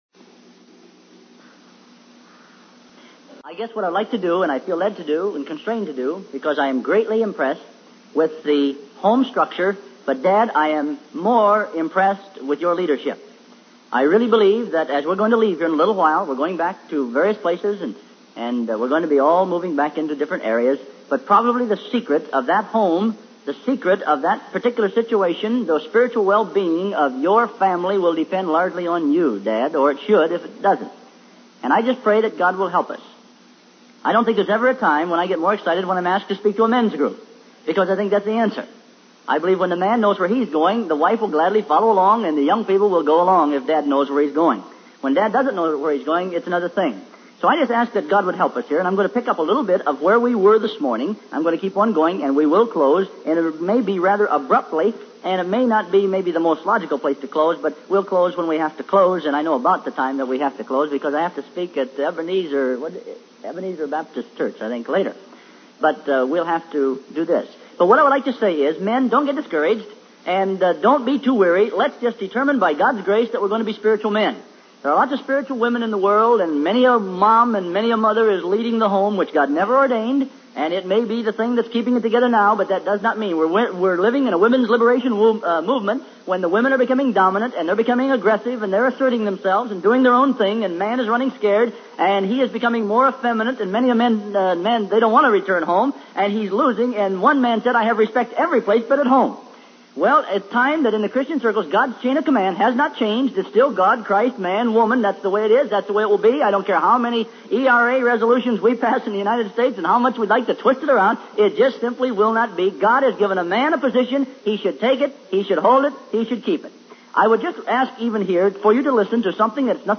In this sermon, the speaker emphasizes the importance of fathers and parents in providing moral instruction to their children. He encourages fathers to take responsibility for teaching their sons and daughters good doctrine and understanding.